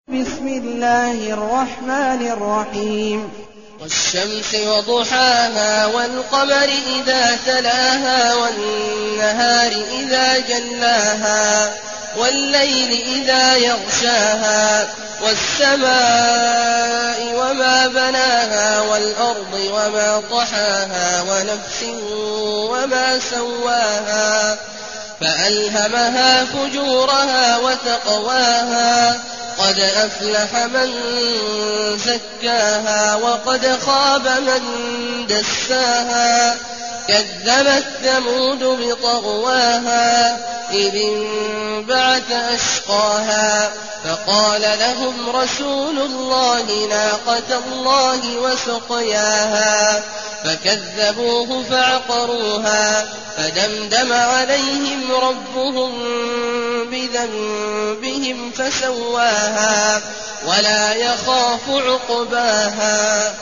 المكان: المسجد الحرام الشيخ: عبد الله عواد الجهني عبد الله عواد الجهني الشمس